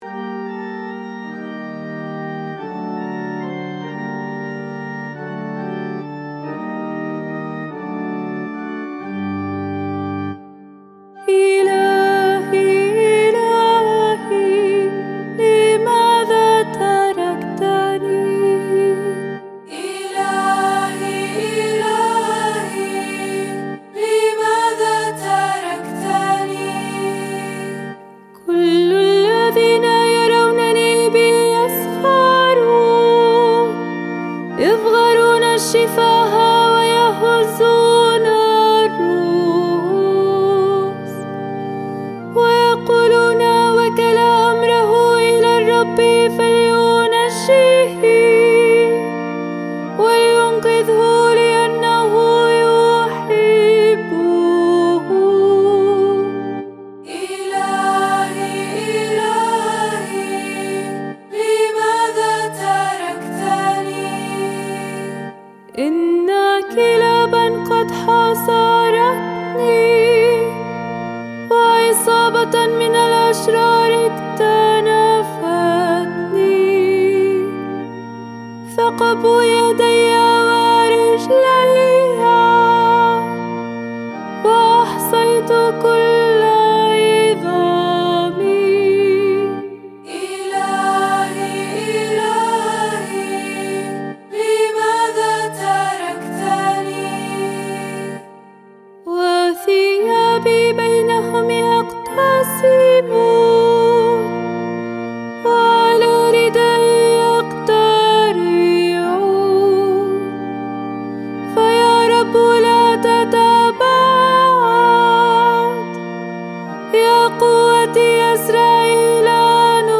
مزمور الردّة لأحد الشعانين (اللحن الأول) (ك. الأسبوع المقدس-ص 58)